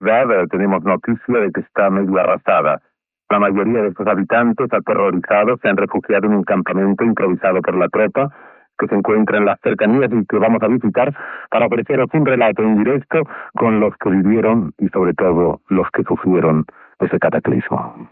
Crònica des d'Hondures, del presentador habitual del programa, Alberto Oliveras, de la situació després del pas de l'huracà Fifi.